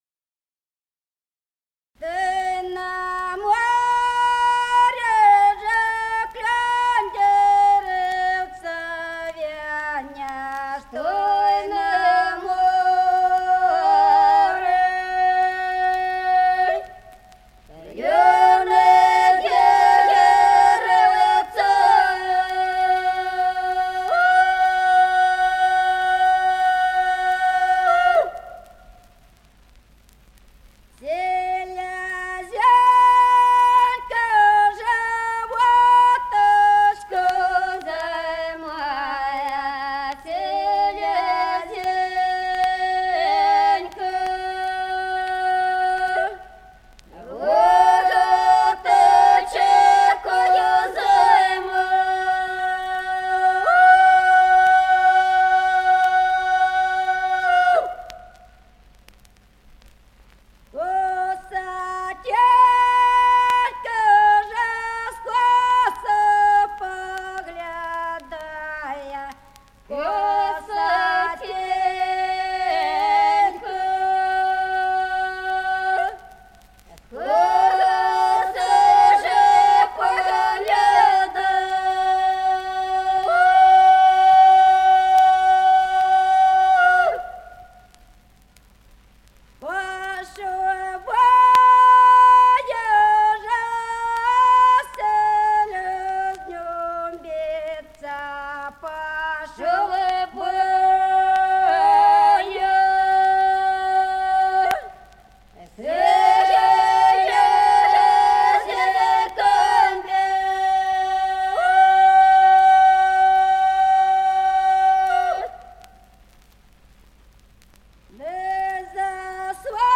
Записано в Москве весной 1966 г. с. Остроглядово.